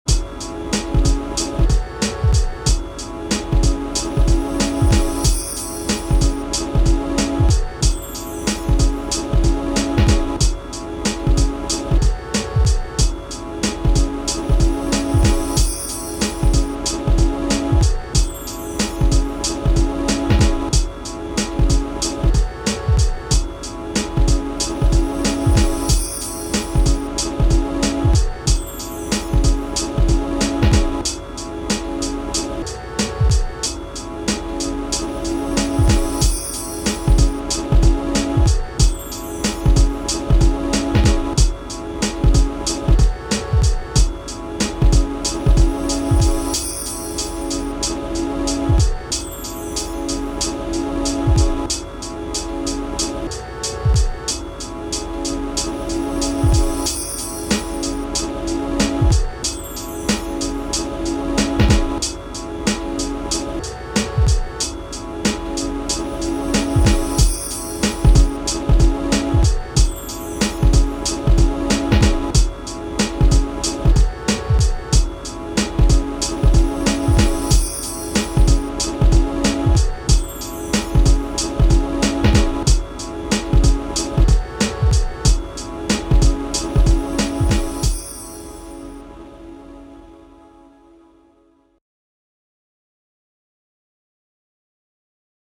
Lo-Fi samplers, beats and things
I finally had a bit of time and spent 2hrs with my Rossum SP.
What a joy to muck around, pitch down, sequence etc.
Here is what came out of my session this morning: